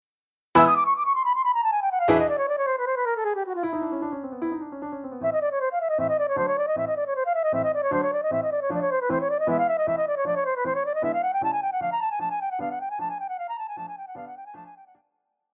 Classical
Flute
Piano
Symphony
Solo with accompaniment